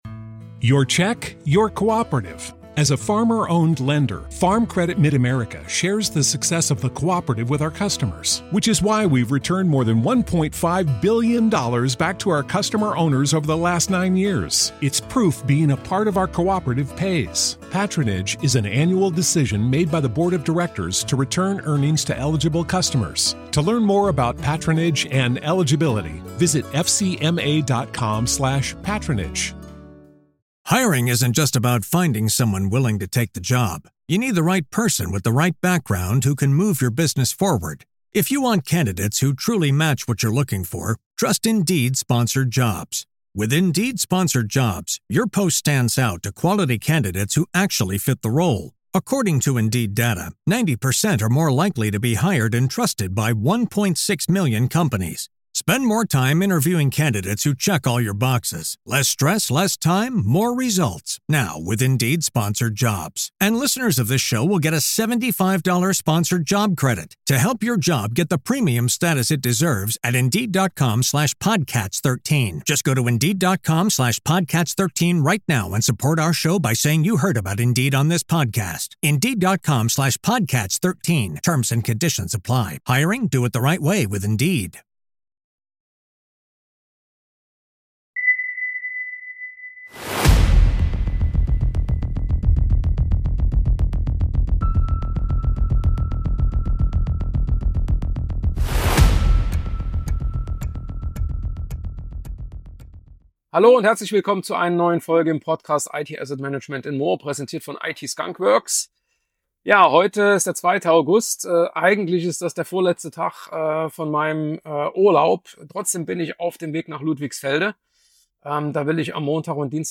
In dieser Folge von spreche ich in einer weiteren On The Road Folge aus der Serie The Life of a CIO - A Report from the Trenches - über genau diese Gratwanderung: Wo hört „normale Software“ auf und wo fängt „KI“ an?